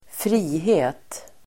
Uttal: [²fr'i:he:t]